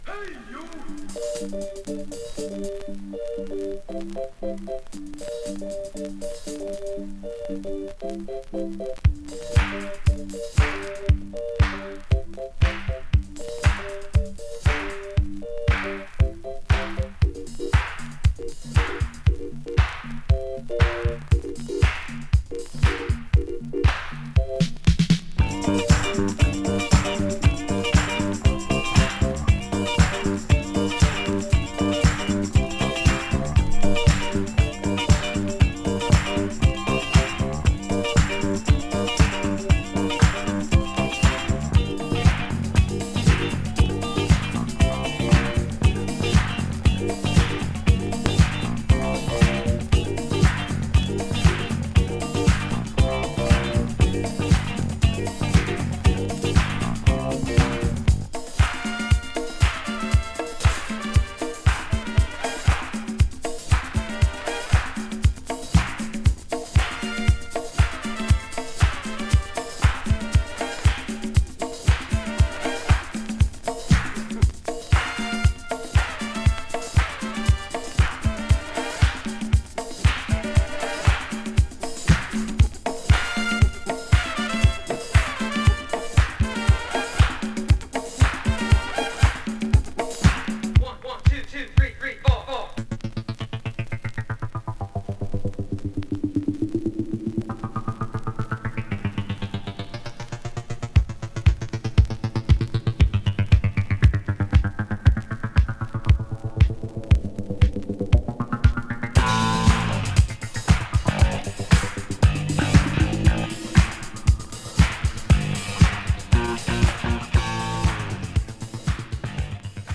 media(A/B): VG/VG スリキズによるチリノイズ、レーベルにシール
Garage Classic！
【DISCO】【BOOGIE】